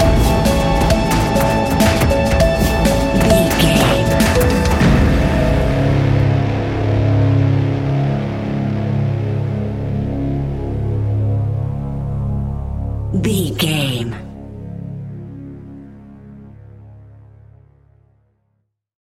Epic / Action
Fast paced
In-crescendo
Ionian/Major
C♯
industrial
dark ambient
EBM
synths
Krautrock